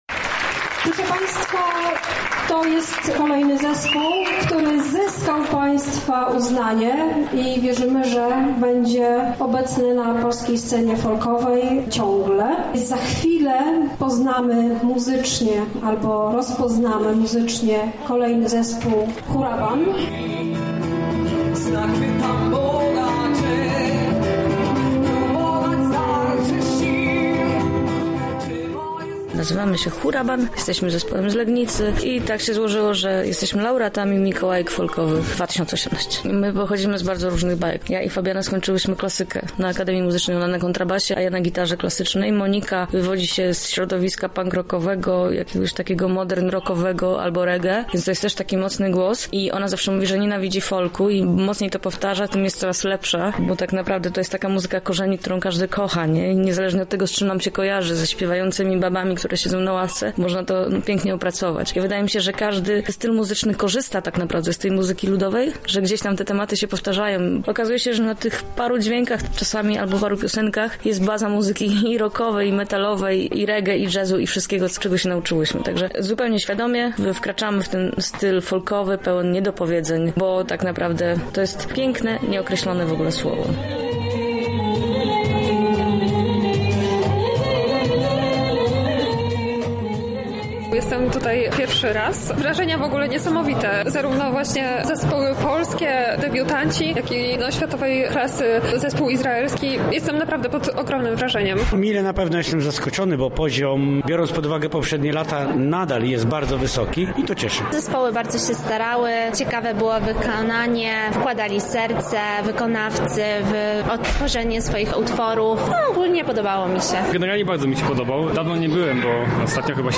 Zgubiłem „w tle” i „na wschód” – tak mnie… poruszyło.